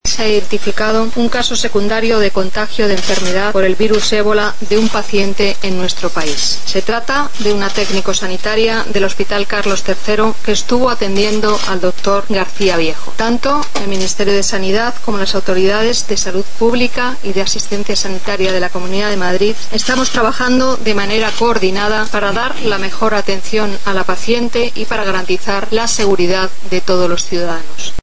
A ministra espanhola da Saúde, Ana Mato, confirmou o caso e diz que a fonte de contágio  está ainda sob análise.
ebola-ministra-saude-espanhola-ana-mato.mp3